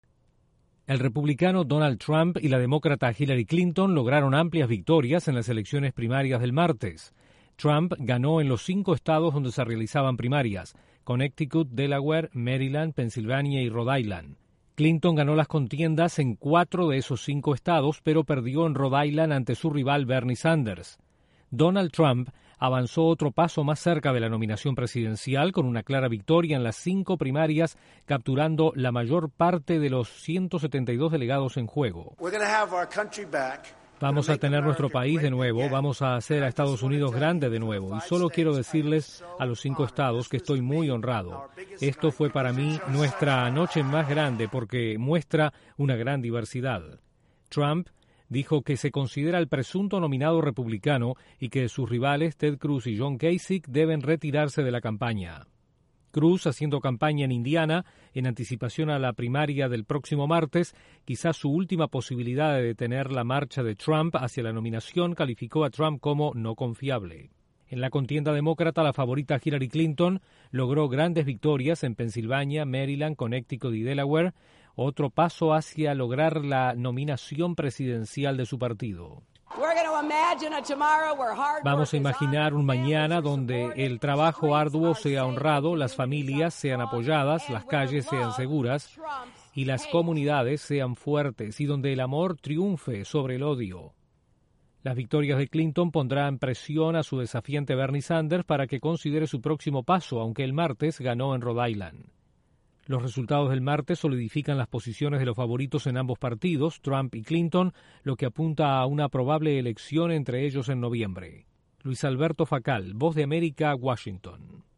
Donald Trump y Hillary Clinton solidifican sus posiciones como favoritos en sus respectivos partidos tras los triunfos del martes en el noreste de Estados Unidos. Desde la Voz de América informa